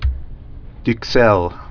(dük-sĕl dk-, dək-)